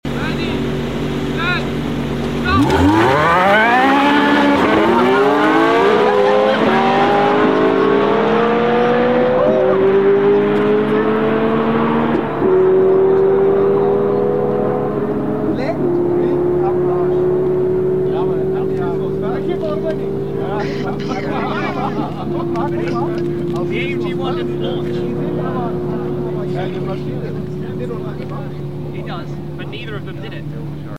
Mercedes AMG ONE vs Porsche 918 sound effects free download